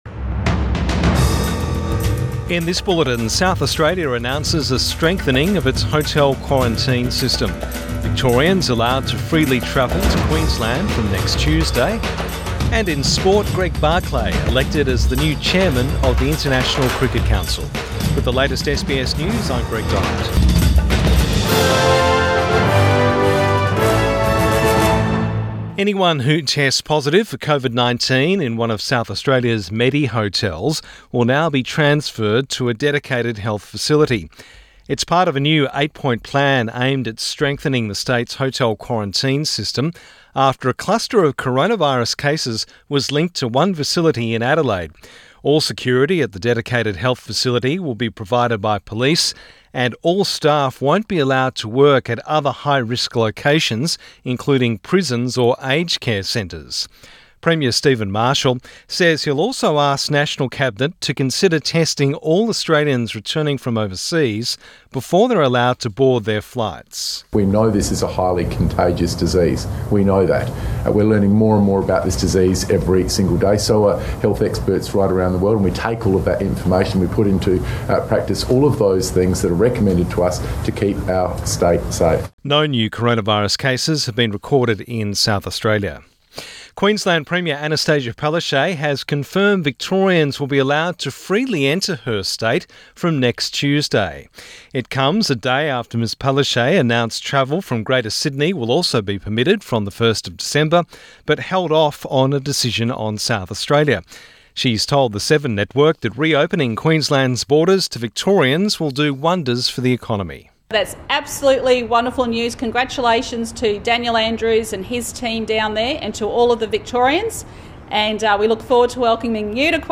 Midday bulletin 25 November 2020